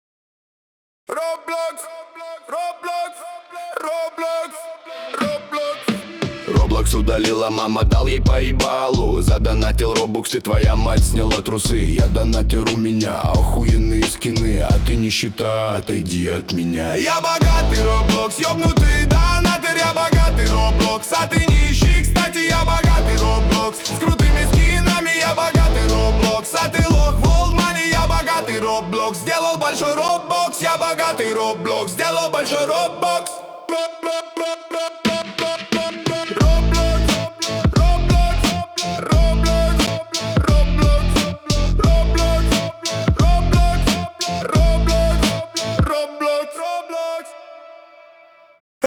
хип-хоп/рэп